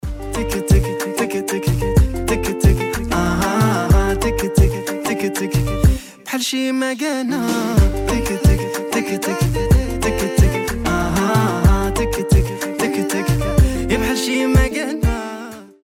танцевальные , рэп , арабские , заводные , ритмичные